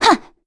Valance-Vox_Attack1_kr.wav